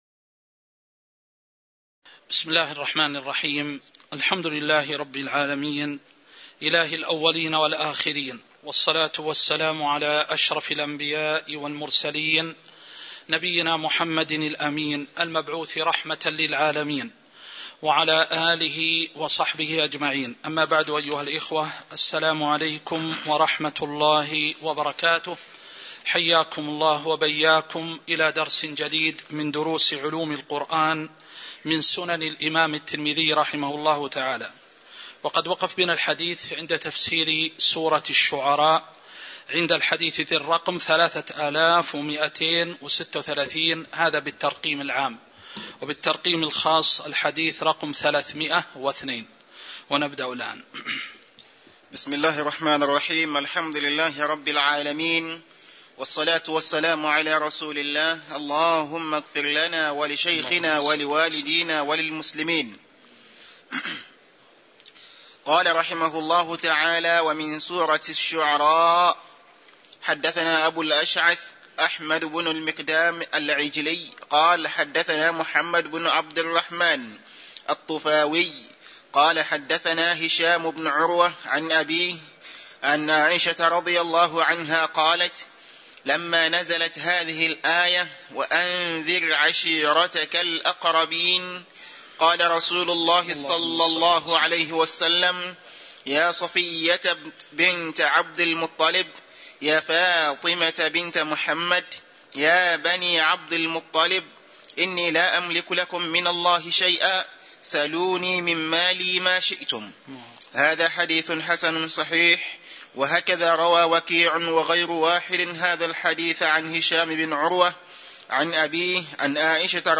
تاريخ النشر ١٢ جمادى الآخرة ١٤٤٣ هـ المكان: المسجد النبوي الشيخ